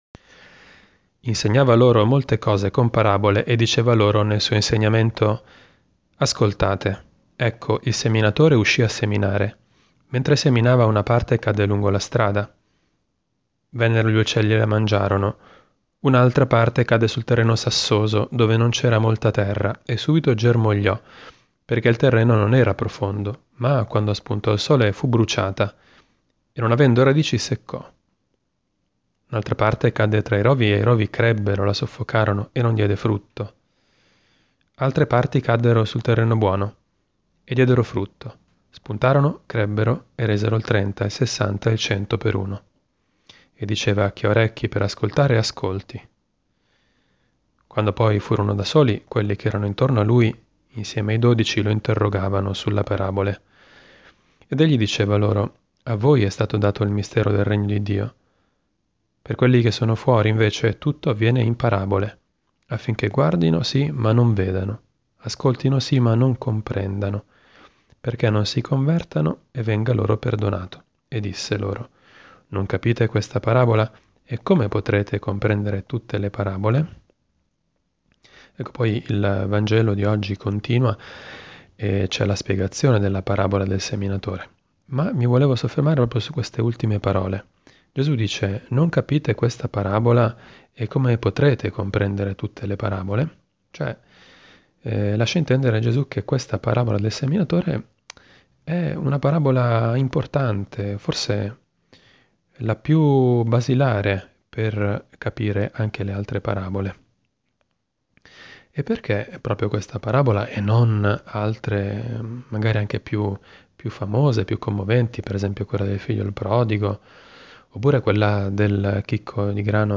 Commento al vangelo